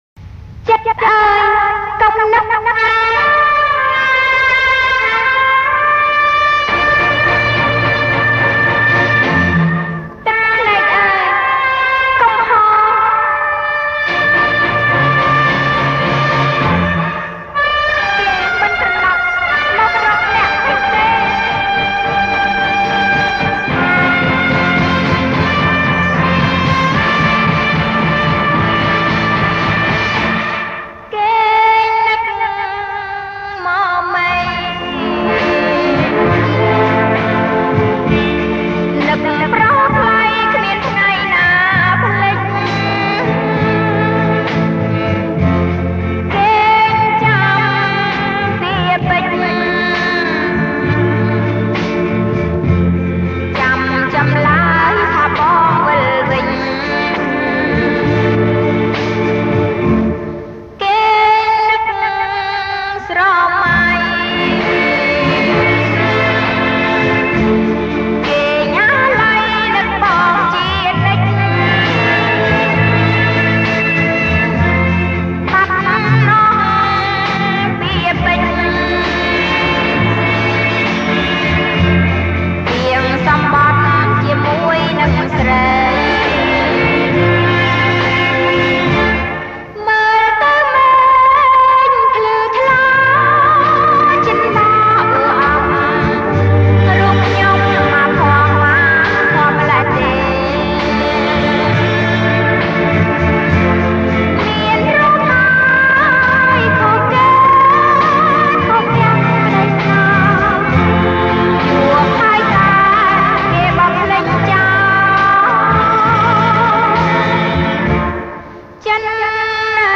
ប្រគំជាចង្វាក់ Slow Rock